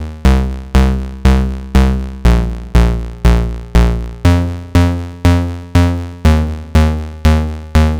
TSNRG2 Bassline 008.wav